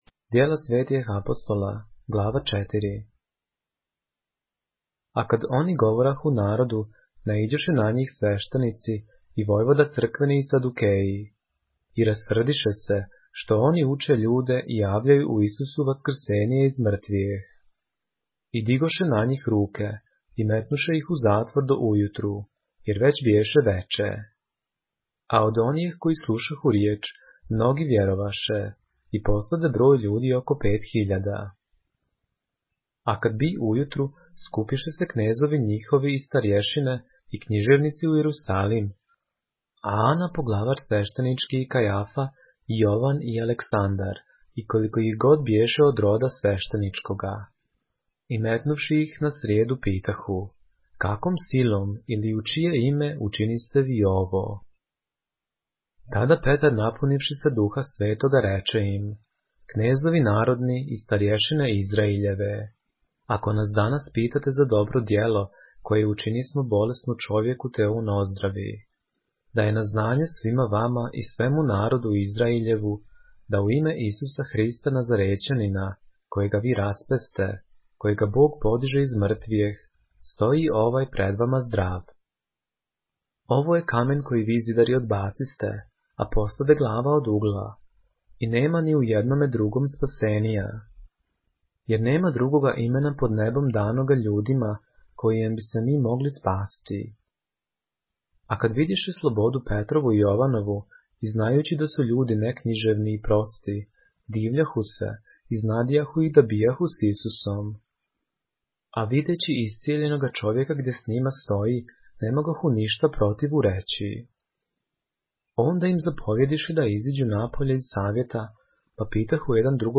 поглавље српске Библије - са аудио нарације - Acts, chapter 4 of the Holy Bible in the Serbian language